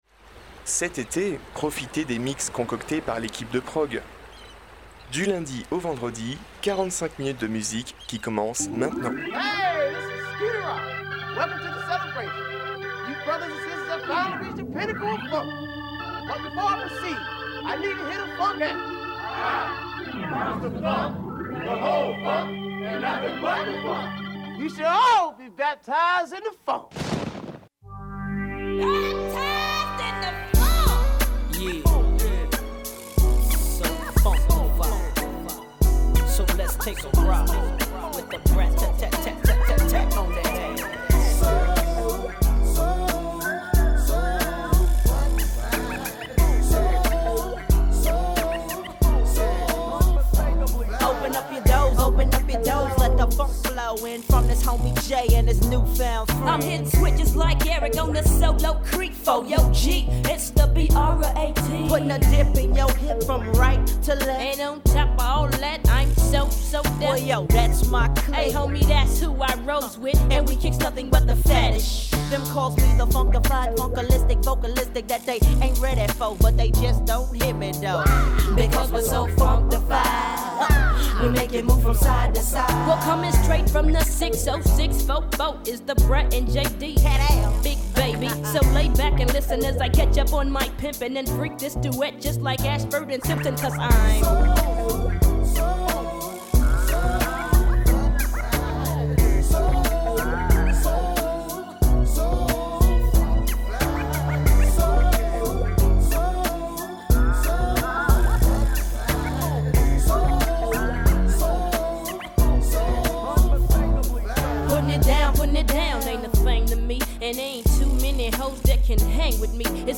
mix féminin